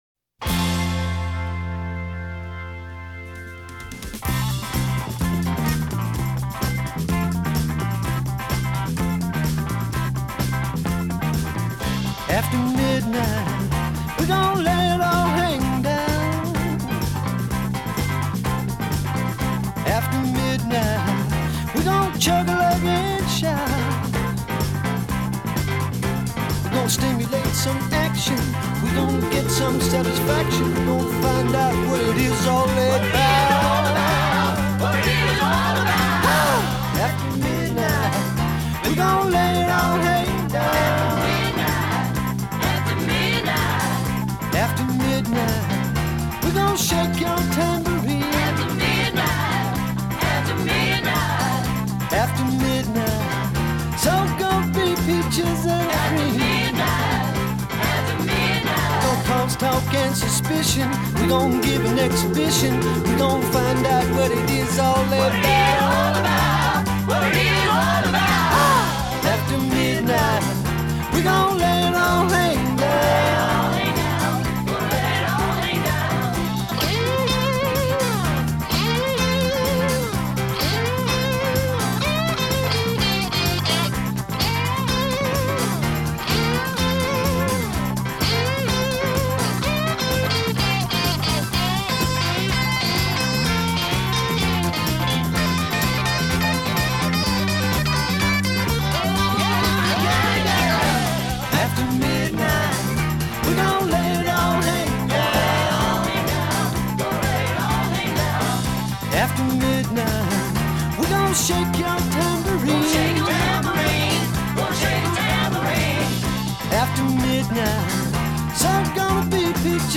• guitar